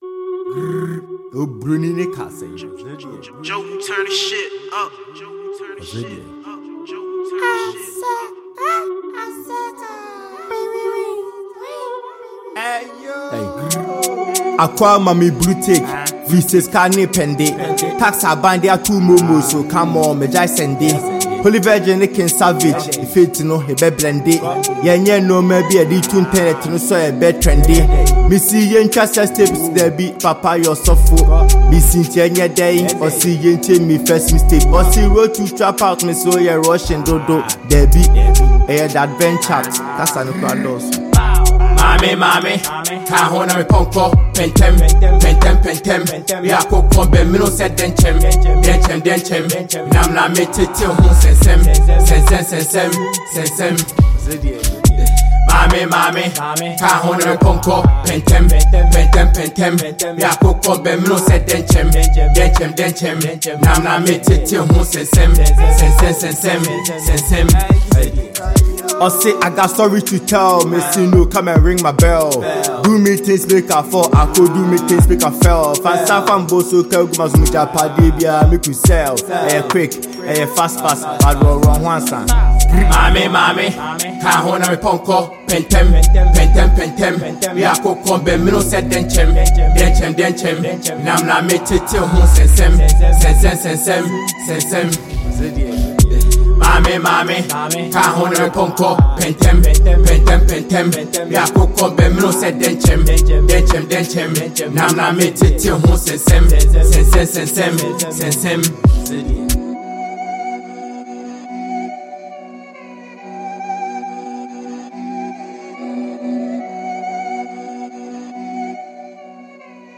Ghanaian trapper